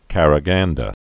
(kărə-gändə) also Qa·ra·ghan·dy (-dē)